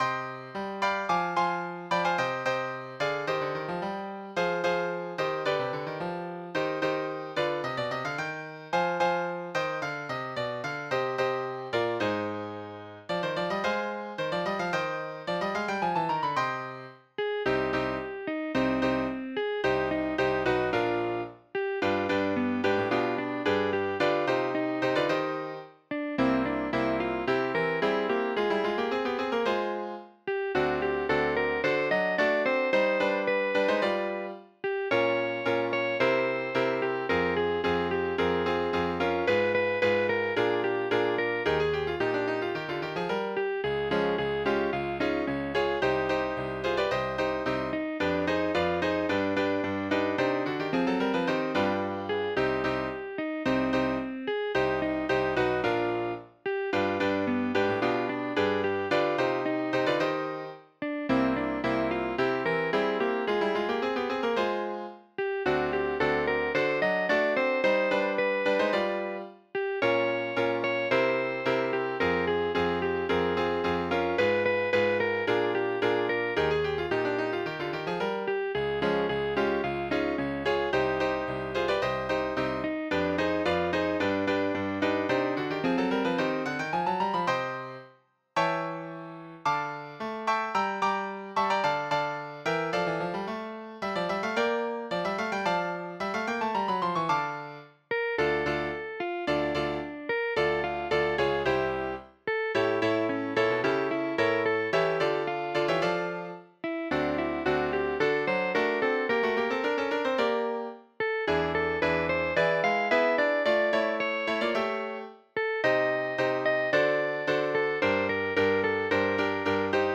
СТИЛЬОВІ ЖАНРИ: Ліричний
ВИД ТВОРУ: Авторська пісня